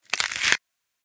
PixelPerfectionCE/assets/minecraft/sounds/mob/skeleton/step4.ogg at mc116